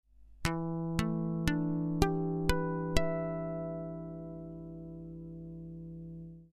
ナイロン弦の装着クラッシックギター風。
ハーモニクスのライン録り
カポ無しハーモニクスのライン録りなmp3
全体的に少しモコモコな気がするのはZII-HGの特徴かしら？
FFTで見るとライン録りでは低域が多目かな？
ZII-HG_Line_Capo0_Ha2.mp3